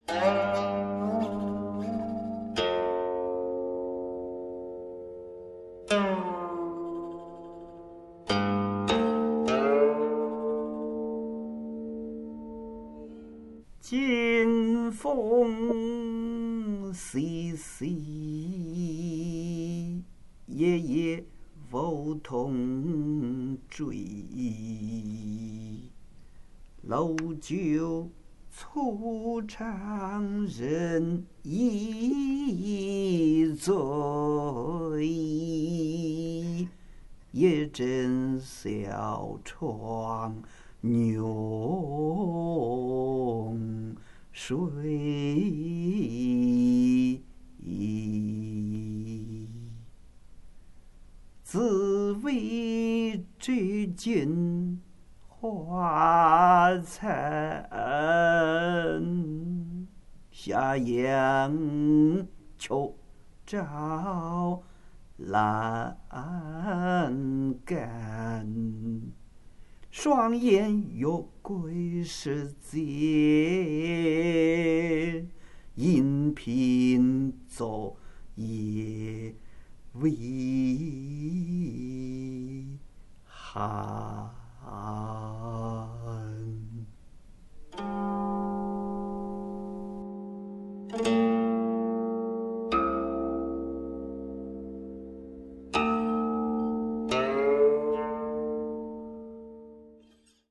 吟唱